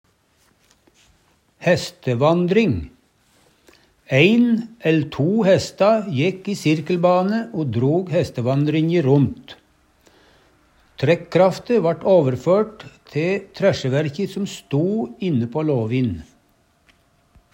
hestevandring - Numedalsmål (en-US)
DIALEKTORD